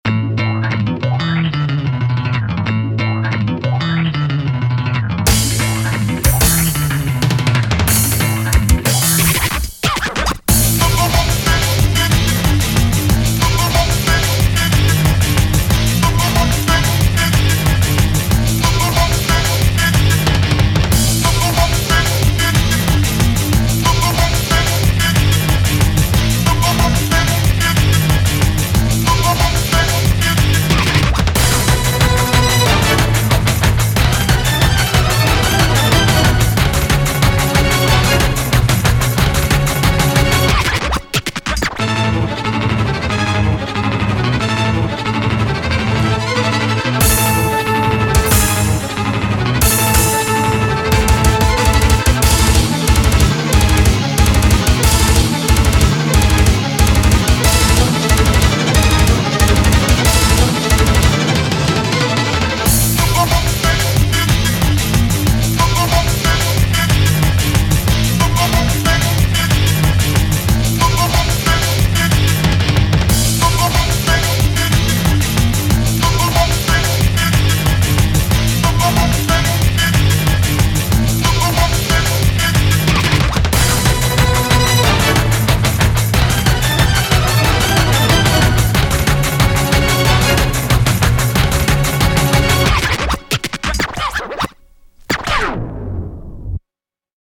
BPM184
Audio QualityPerfect (High Quality)